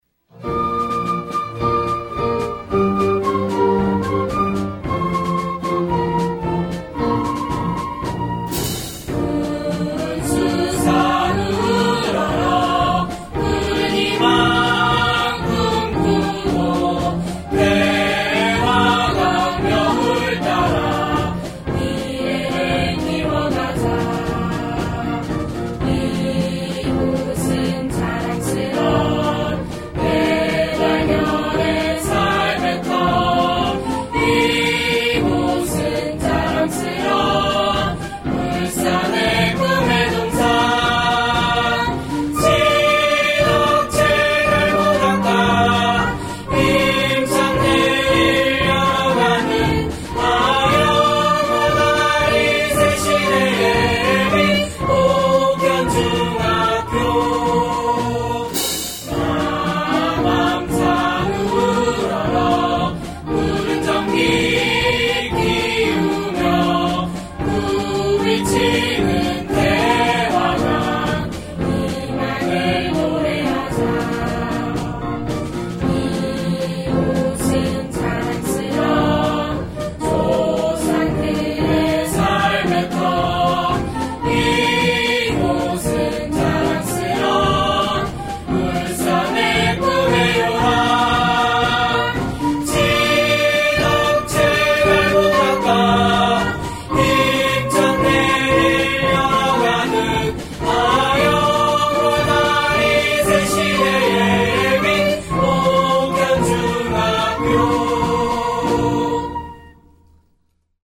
옥현중학교 교가 음원 :울산교육디지털박물관
옥현중학교의 교가 음원으로 김성춘 작사, 김정호 작곡이다.